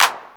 TR909CLAP.wav